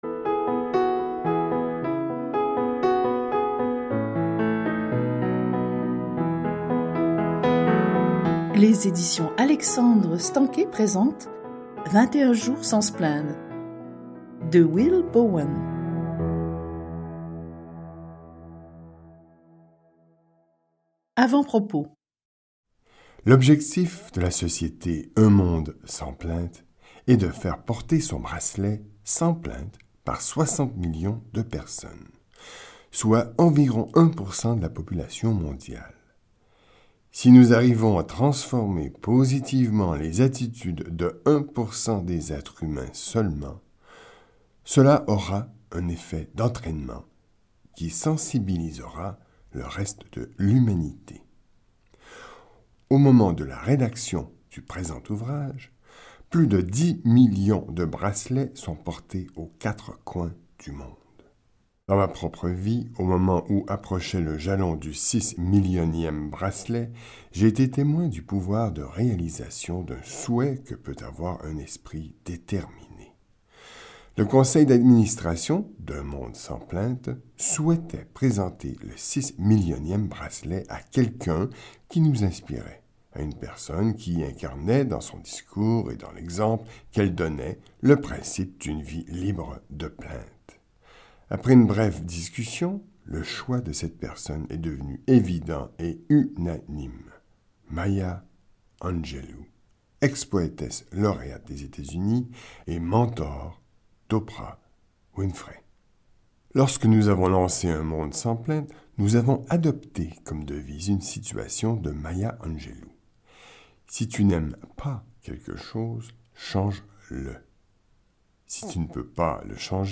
Dans ce livre audio, Will Bowen nous enseigne à enrayer cette mauvaise habitude une bonne fois pour toutes.